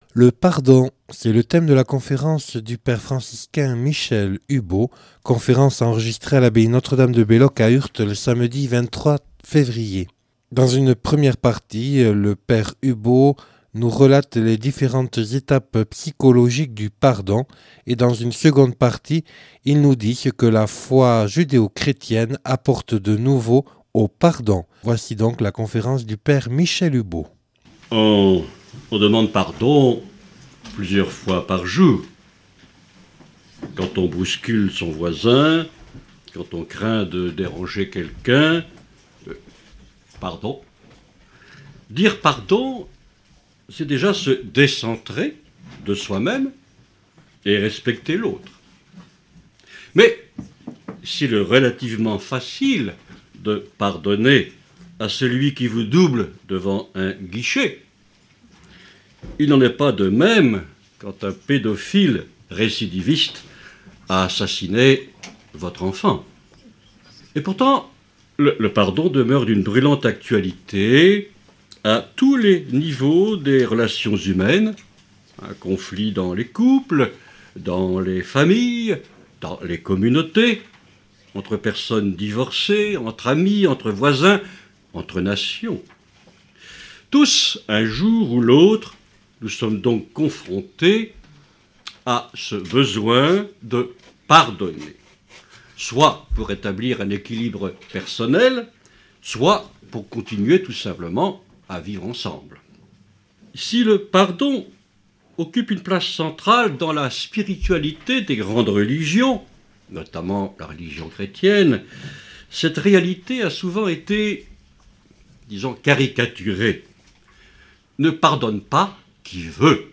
(Enregistré le 23/02/2008 à l'abbaye de Belloc)